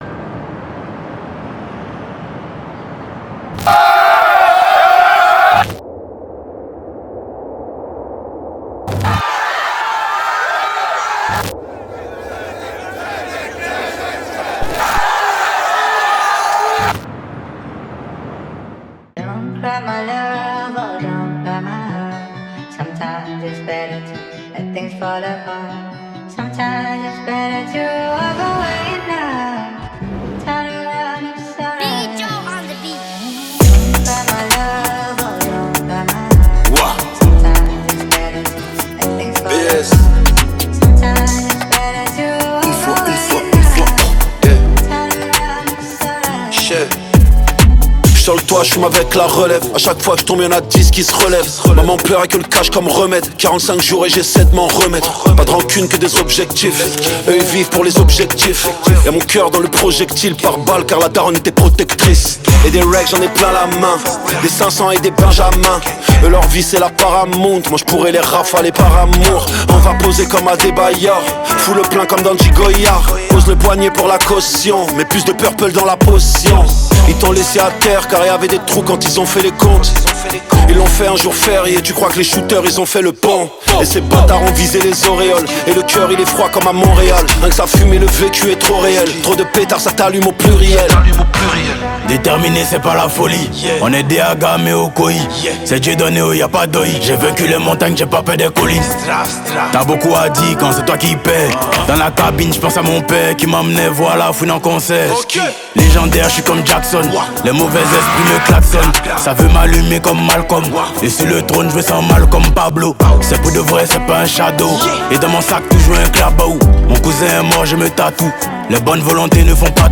18/100 Genres : french rap, french r&b Écouter sur Spotify